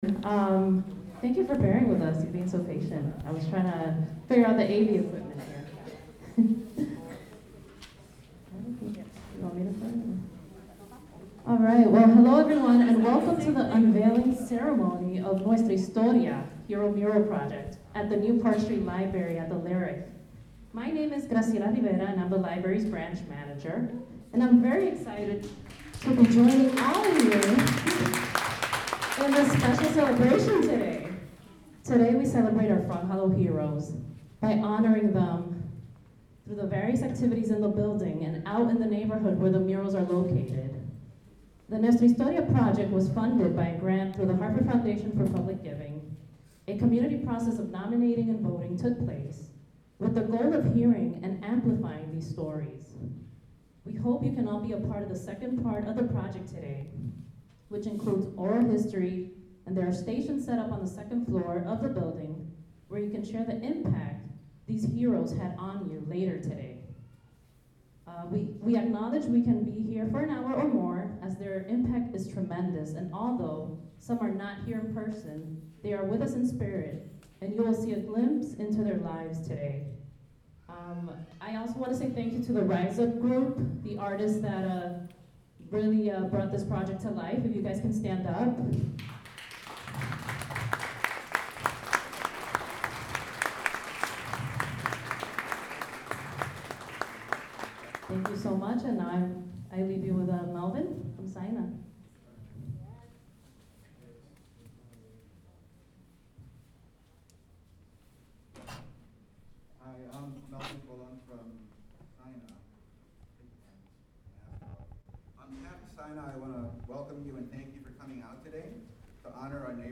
Ceremony.MP3 Pt. 2 Ceremony.MP3 Dublin Core Title Mural Unveiling Ceremony Subject Frog Hollow Heroes Description The nine heroes of Frog Hollow are each represented by a mural recognizing their efforts within the community.